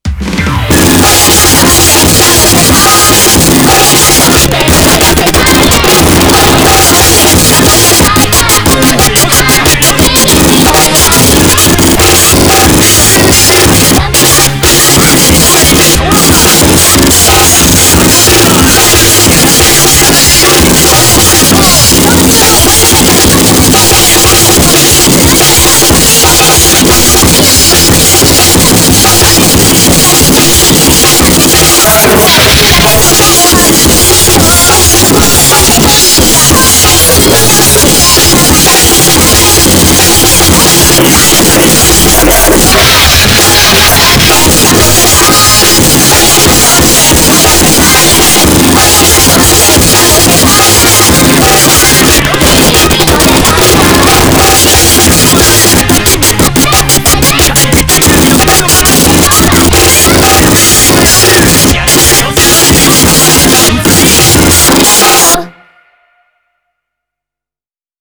You can just take any sample, warp it to match desired BPM, and throw the sliced drum break over the top.
Voila, you've just made some goofy ass """le breakcore""".
(WARNING VERY VERY LOUD) Listen